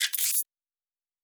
pgs/Assets/Audio/Sci-Fi Sounds/Weapons/Additional Weapon Sounds 5_3.wav at master
Additional Weapon Sounds 5_3.wav